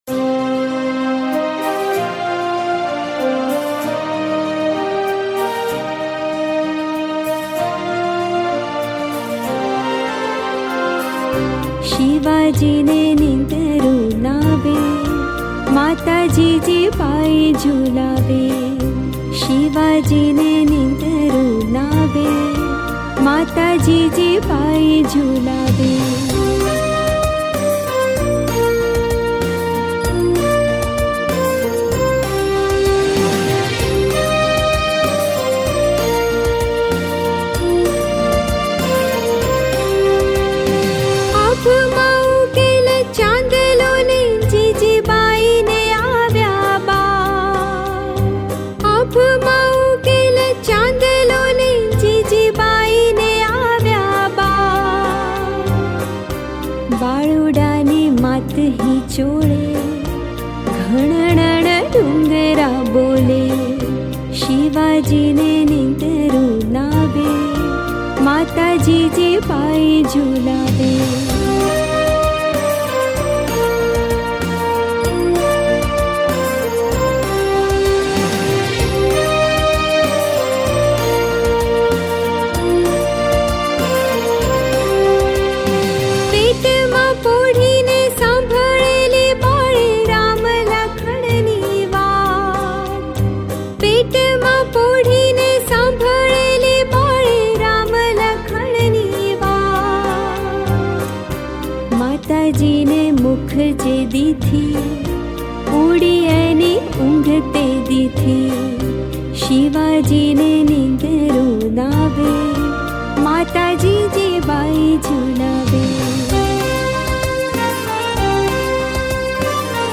ગીત સંગીત ભજનાવલી - Bhajans